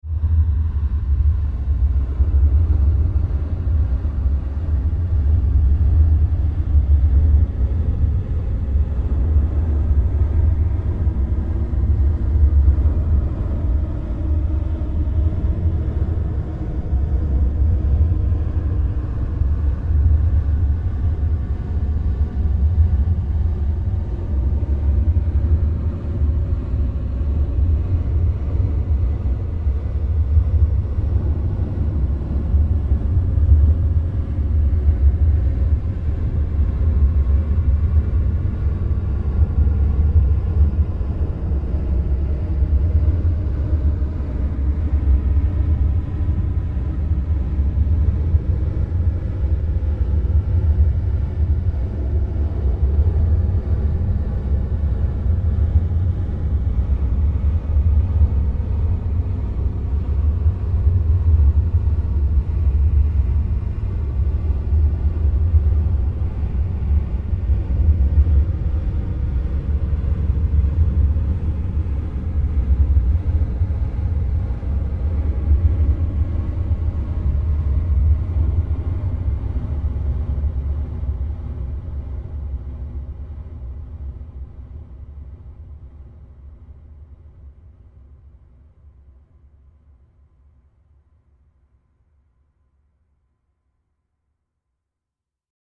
Extra Long Sound Effect - 1m 35s
Use This Extra Long Premium Hollywood Studio Quality Sound In Stereo.
Channels: 2 (Stereo)
This Premium Quality Futuristic Sound Effect
Tags: long large alien beam beams future futuristic laser sci-fi science fiction scifi